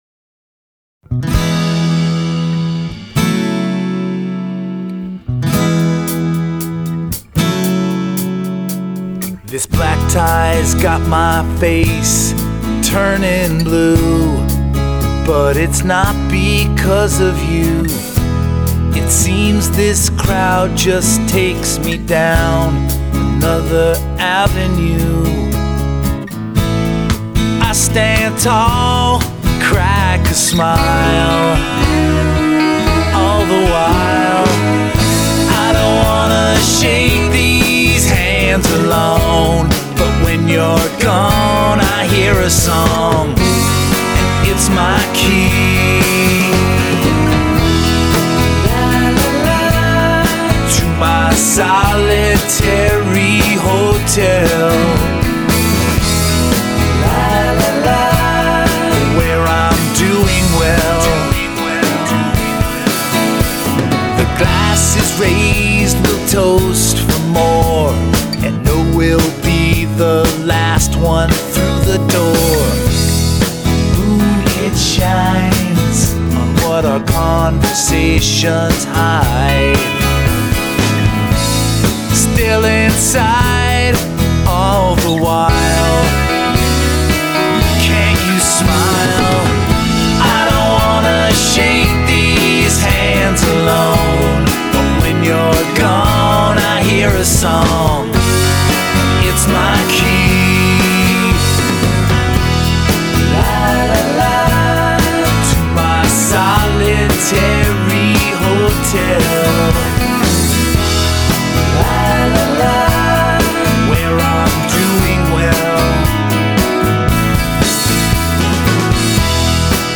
polished, subtlely hooky, sometimes amusing